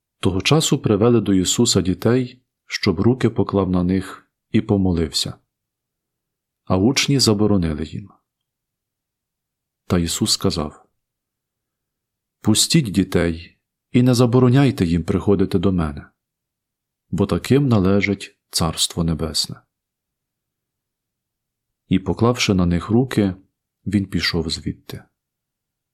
Євангеліє